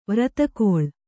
♪ vratakoḷ